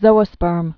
(zōə-spûrm)